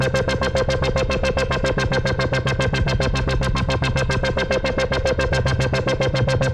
Index of /musicradar/dystopian-drone-samples/Tempo Loops/110bpm
DD_TempoDroneA_110-C.wav